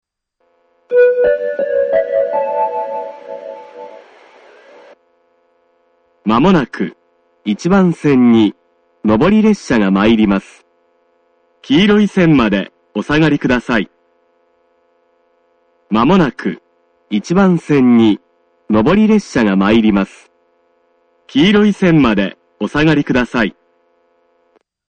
この駅は放送・メロディーとも音量が非常に大きいです。
１番線接近放送
omika-1bannsenn-sekkinn1.mp3